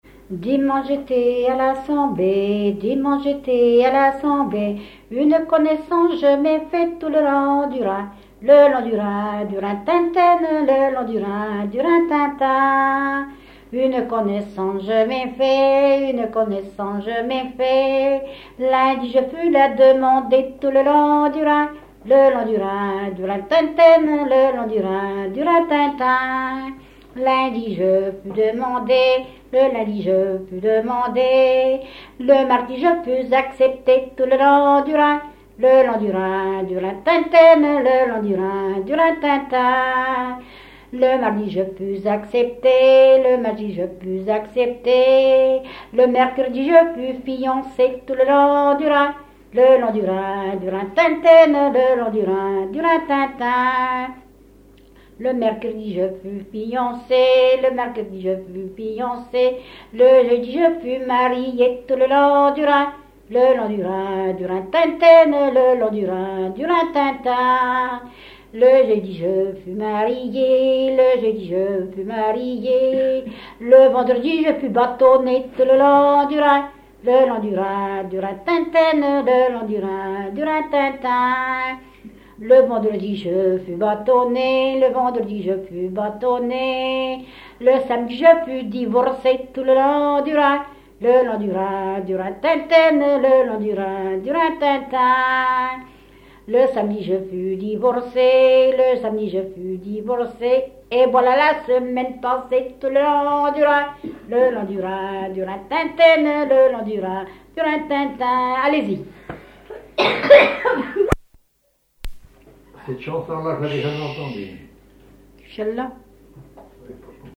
Base d'archives ethnographiques
Genre énumérative
Catégorie Pièce musicale inédite